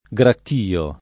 vai all'elenco alfabetico delle voci ingrandisci il carattere 100% rimpicciolisci il carattere stampa invia tramite posta elettronica codividi su Facebook gracchio [ g rakk & o ] s. m. («un gracchiare continuato»); pl.